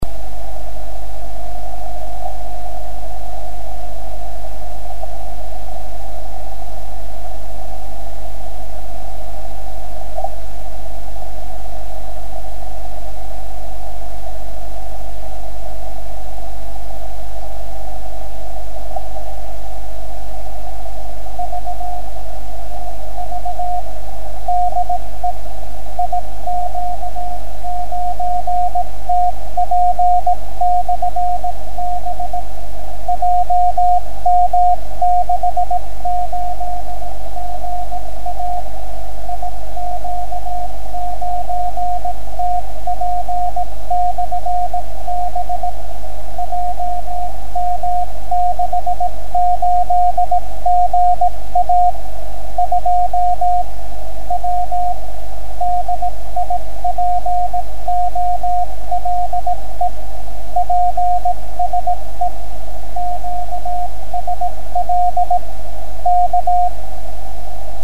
Die hier aufgeführten Stationen wurden von mir selbst empfangen.